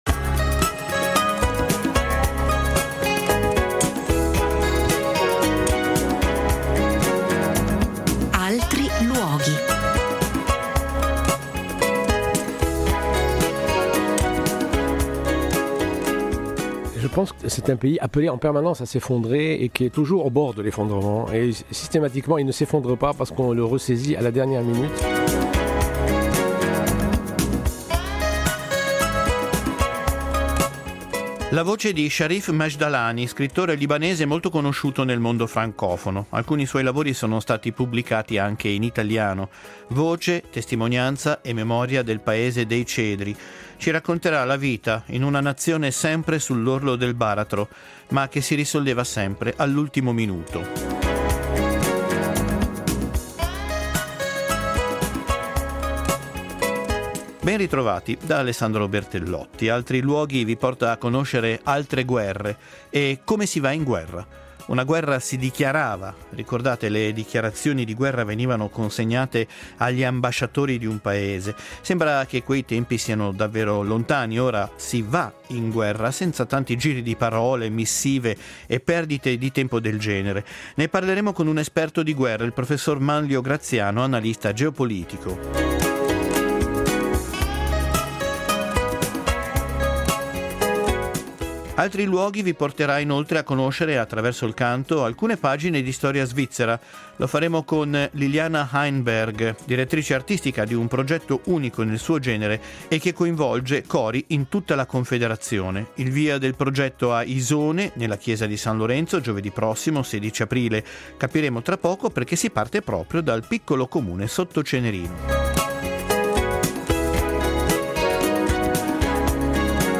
Una voce da Beirut ci porterà verso altriluoghi in cui il rumore delle esplosioni è quotidiano.